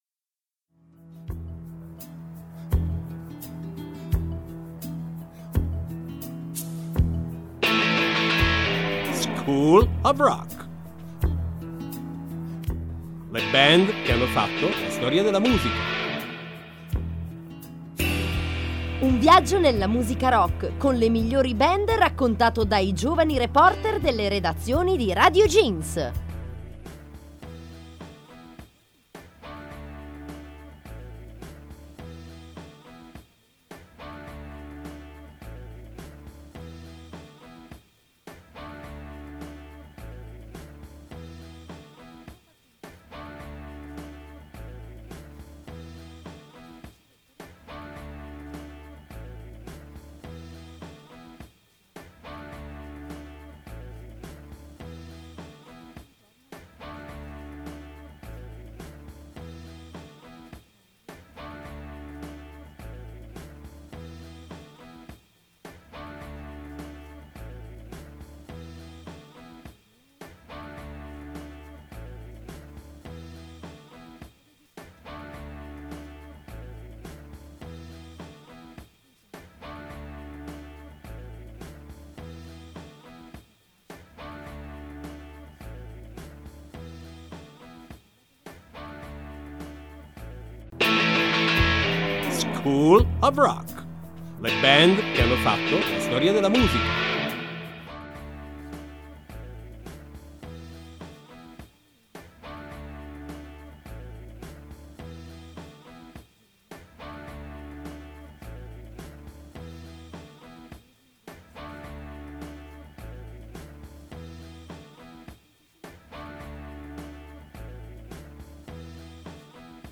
play_circle_filled School of Rock | 29/09/2012 School Of Rock - Eric Clapton Radioweb C.A.G. di Ventimiglia Due delle nostre simpatiche voci ci raccontano in un viaggio musicale la storia del famoso chitarrista Eric Clapton.